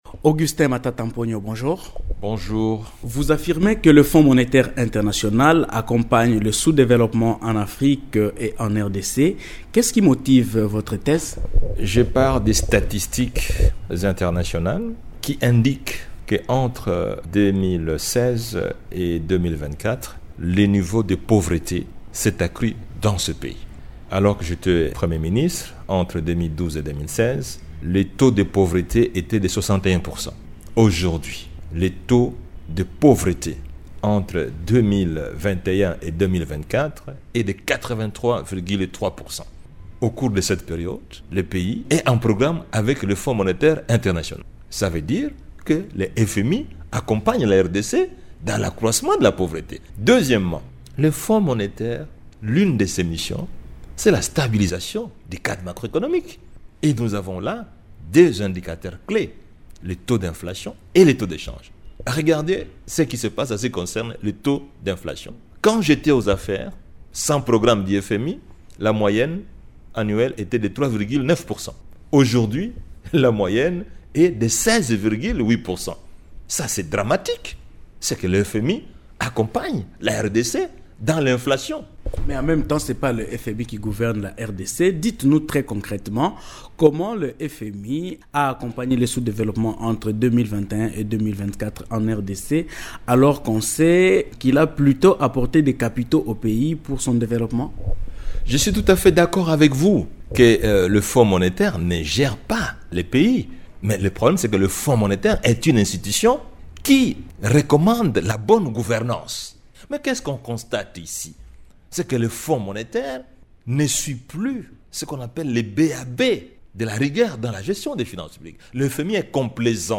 Invité de Radio Okapi, l’ancien Premier ministre Augustin Matata Ponyo estime que « ce programme viendra accroitre davantage la dette publique de la RDC ».